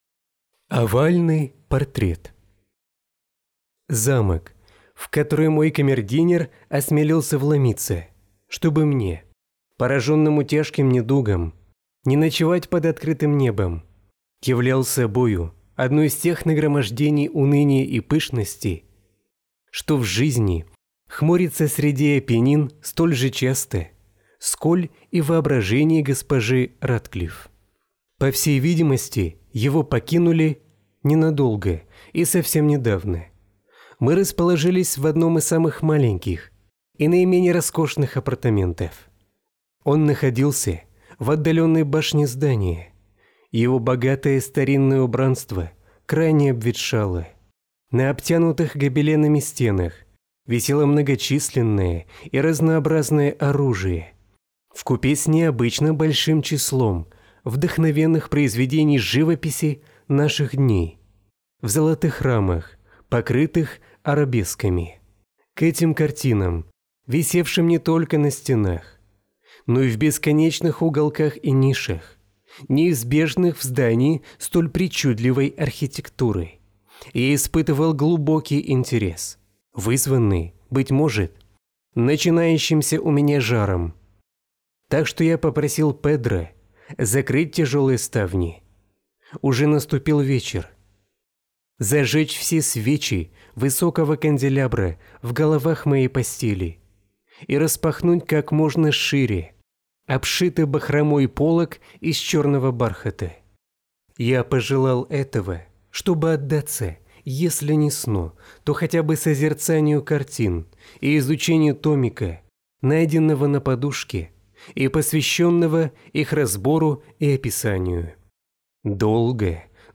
Овальный портрет - аудио рассказ Эдгара По - слушать онлайн